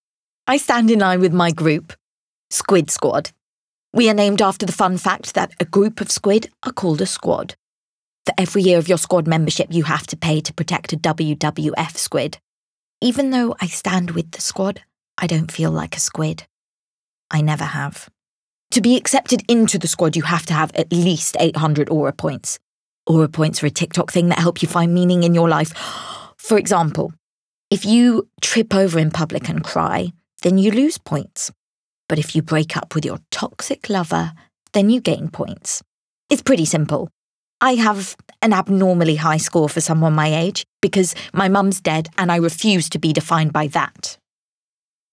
RP ('Received Pronunciation')
Acting, Emotions, Versatile, Energetic, Diverse